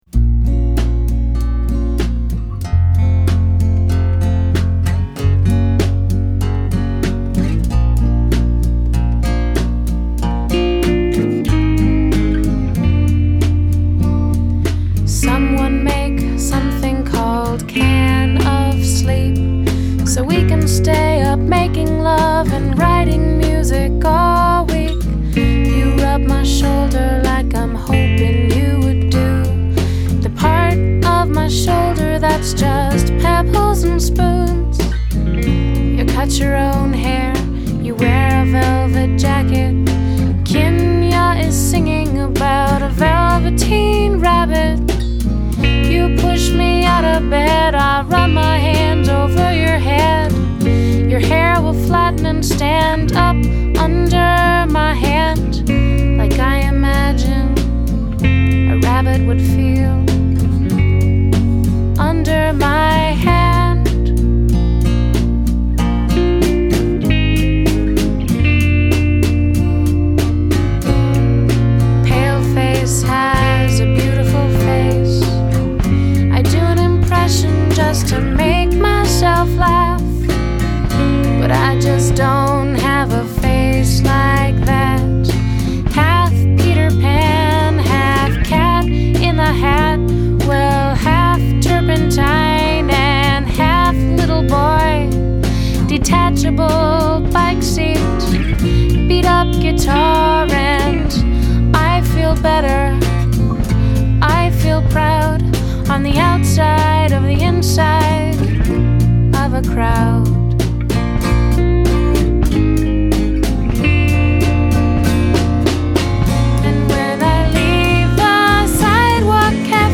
Lyricist, guitarist, singer and poet